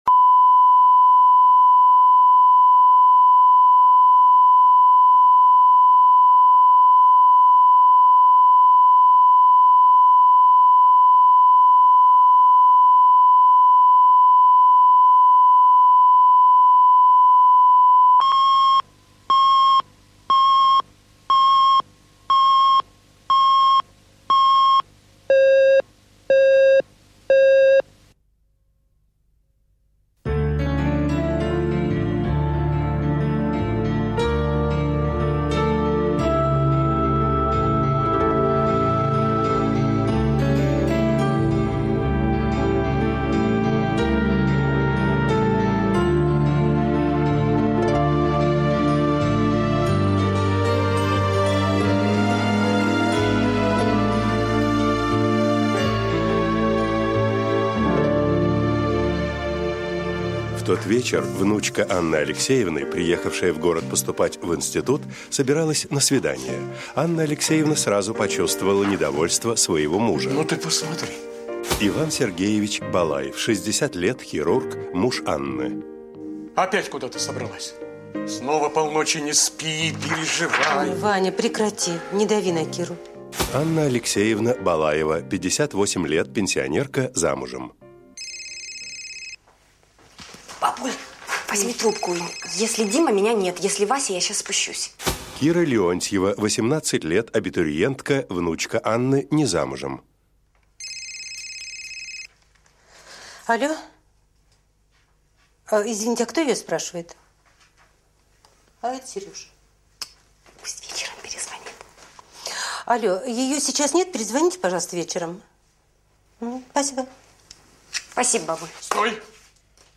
Аудиокнига Человек из прошлого | Библиотека аудиокниг